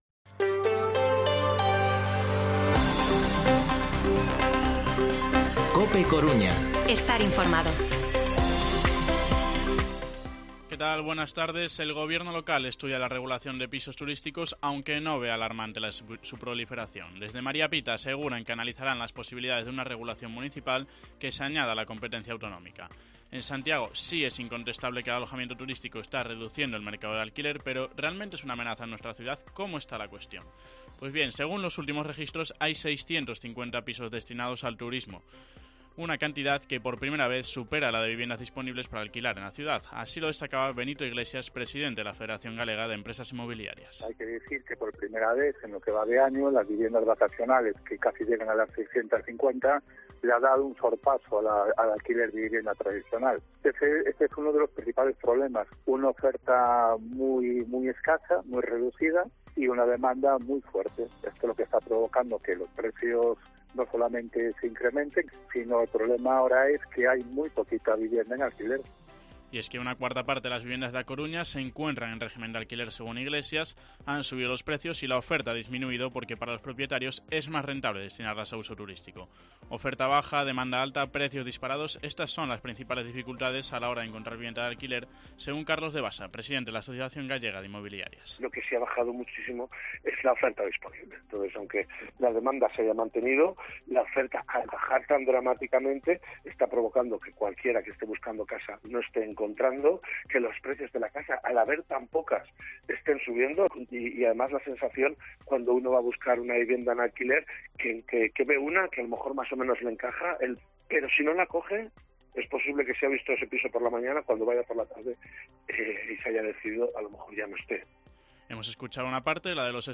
Informativo Mediodía COPE Coruña viernes, 12 de agosto de 2022 14:20-14:30